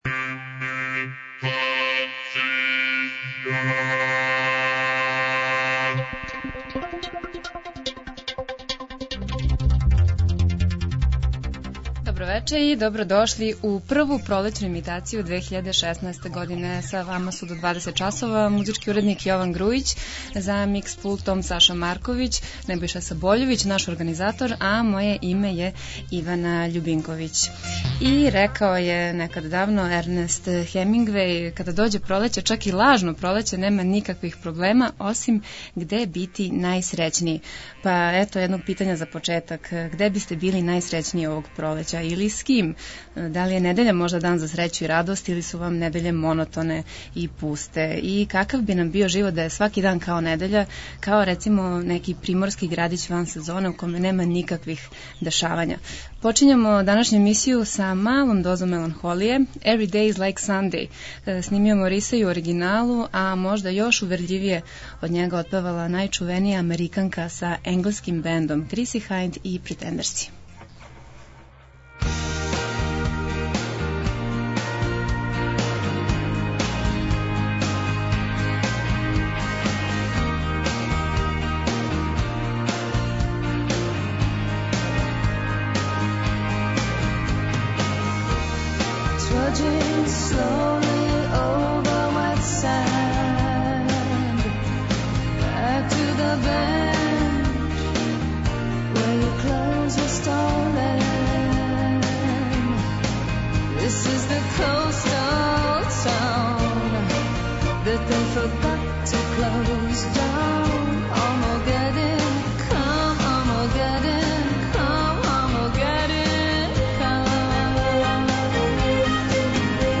Имитација је емисија у којој се емитују обраде познатих хитова домаће и иностране музике.